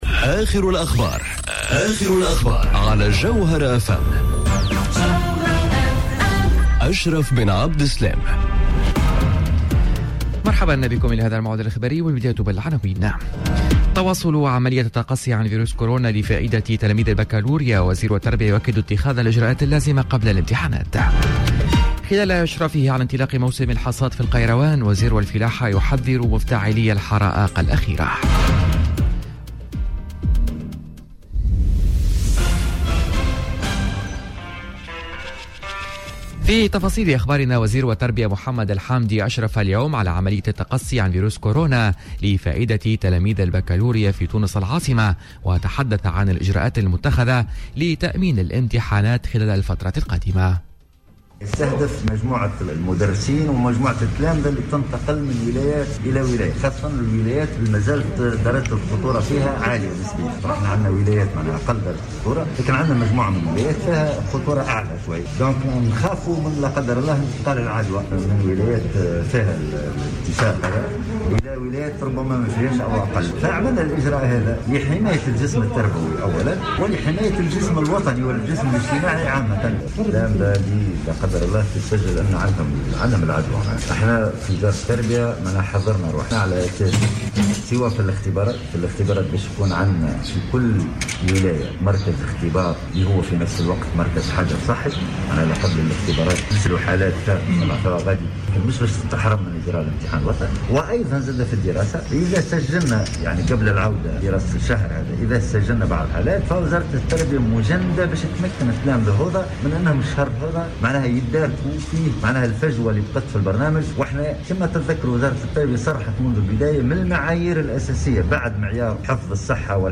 نشرة أخبار منتصف النهار ليوم الإربعاء 27 ماي 2020